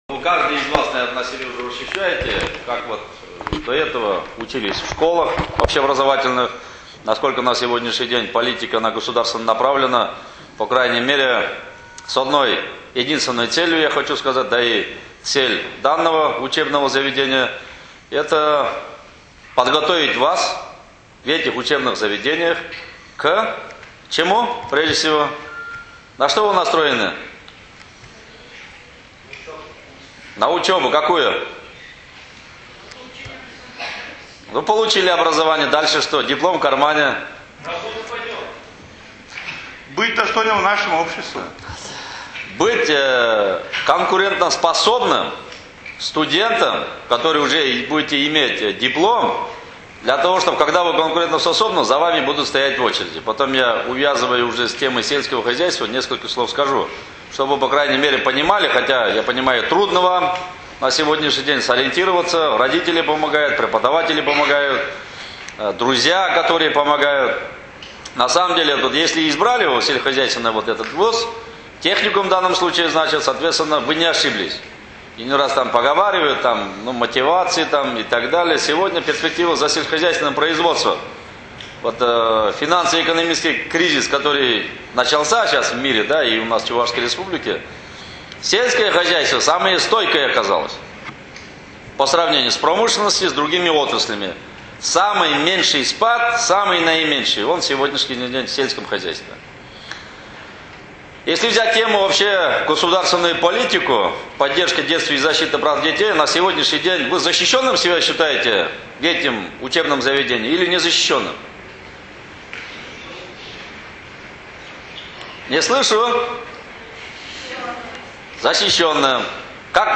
Министр подчеркнул, что они должны быть конкурентоспособными работниками отрасли сельского хозяйства, которая является одной из самых перспективных отраслей. (Аудиозапись выступления)